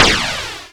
attack_a.wav